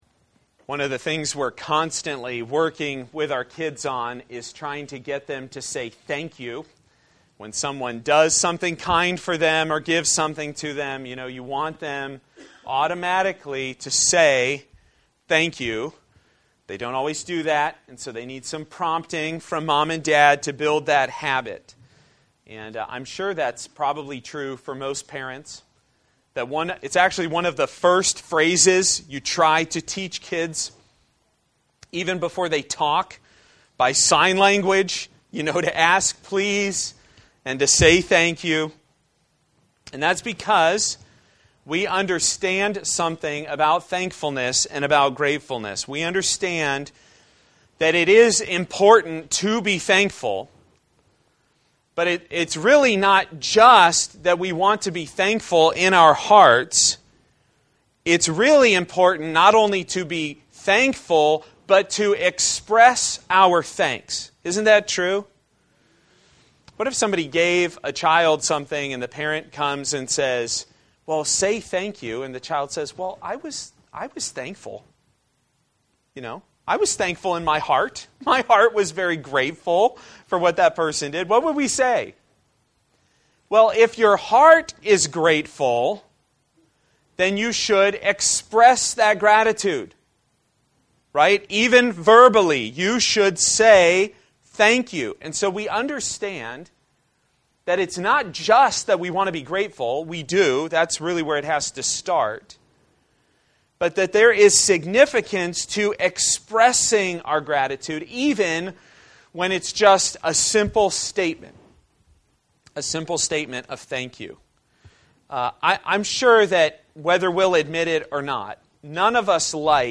A message from the series "Communion." Psalm 107:1-9